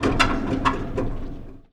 metal_rattle_spin_med_02.wav